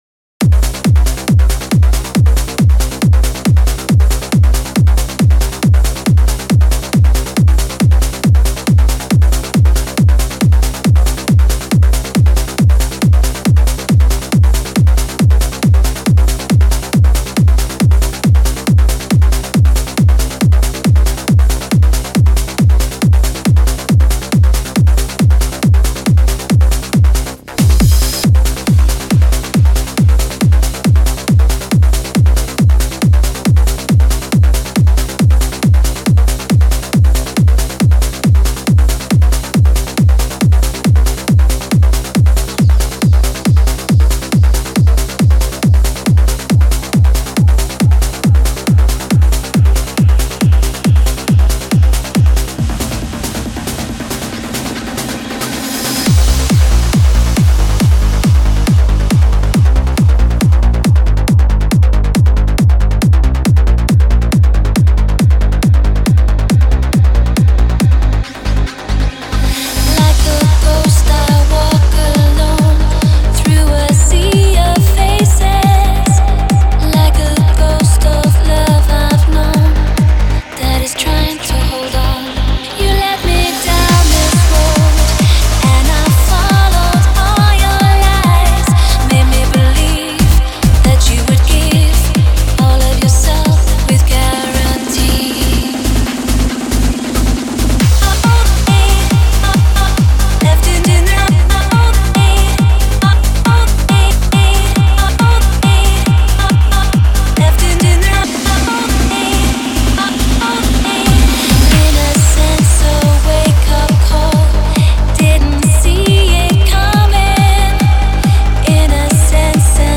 клубная Trance музыка
транс музыка в машину